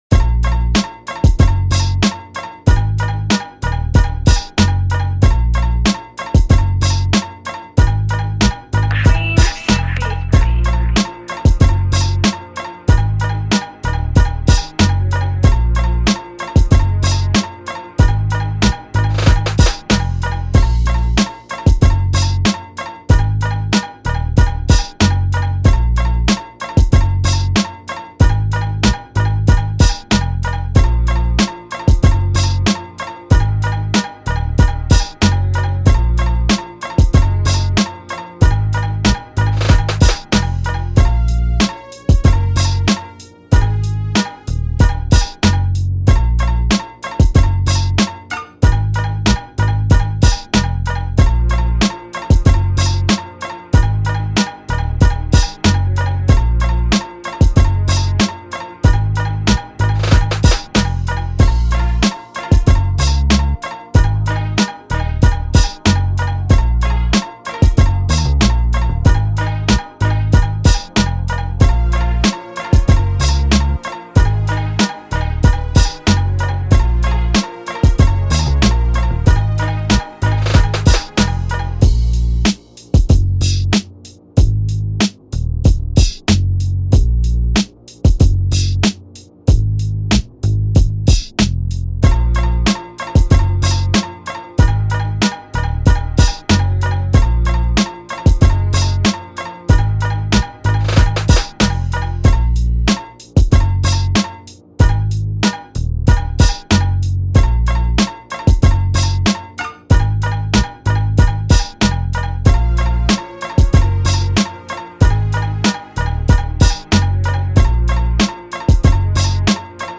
Hip Hop Rap Instrumental beat